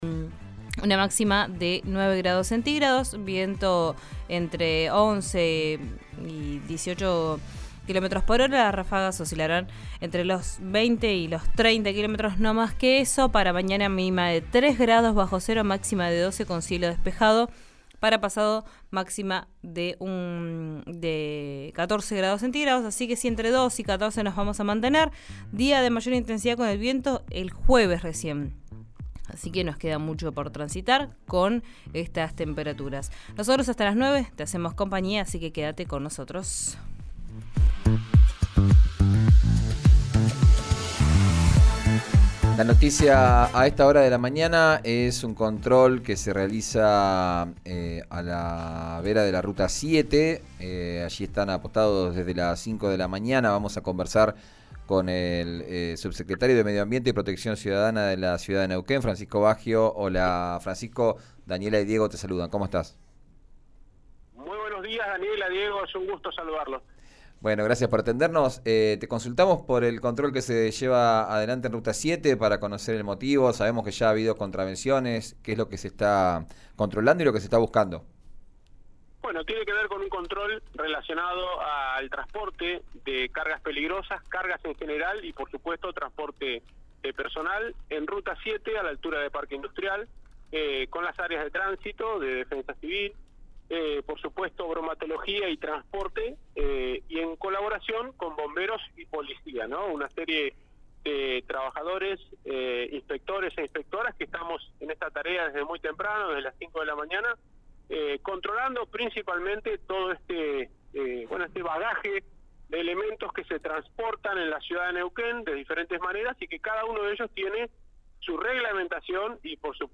Esta mañana, el municipio de Neuquén inició un operativo de control de cargas peligrosas en la Ruta 7. El subsecretario de Medio Ambiente y Protección Ciudadana, Francisco Baggio, comunicó a RÍO NEGRO RADIO que se realizó desde las cinco hasta aproximadamente las nueve, entre las rotondas de Parque Industrial y la fábrica Fasinpat. Retuvieron vehículos por falta de documentación, habilitación e higiene.
Escuchá a Francisco Baggio, en RÍO NEGRO RADIO